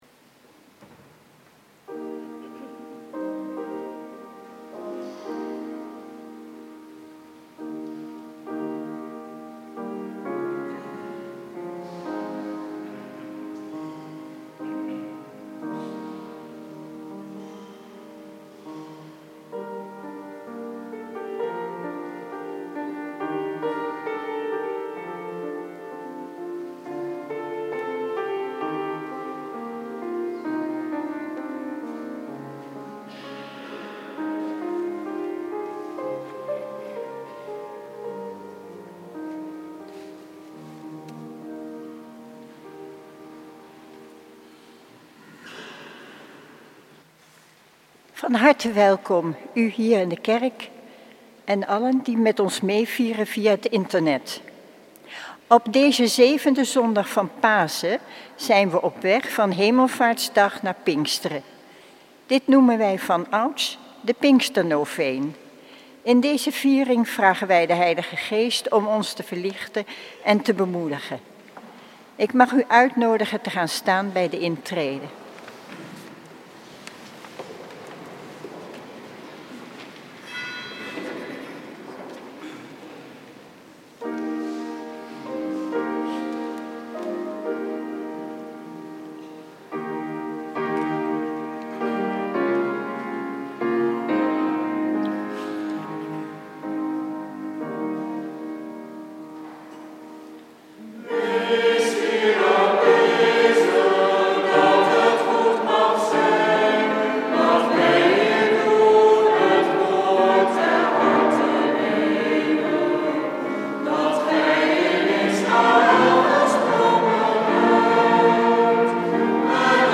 Preek 7e zondag van Pasen, jaar B, 20 mei 2012 | Hagenpreken
Eucharistieviering beluisteren vanuit de H. Willibrordus te Wassenaar. (MP3)